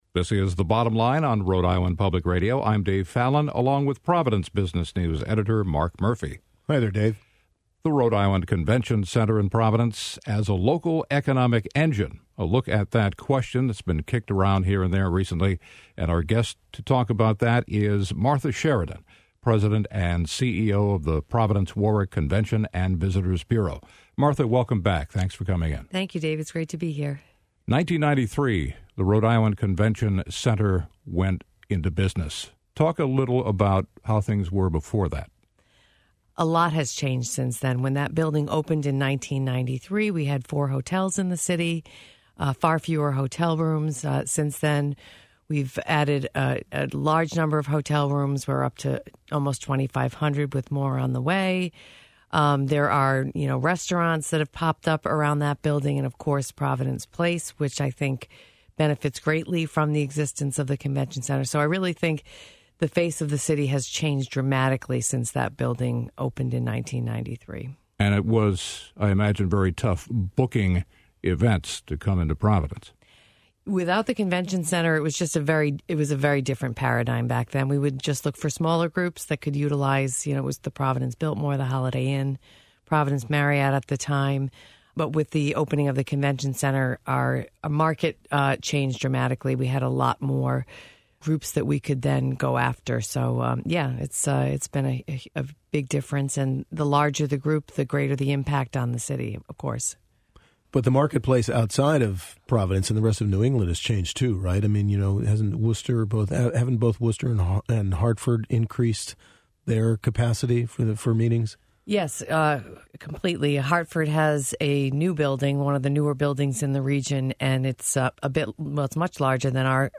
weekly business segment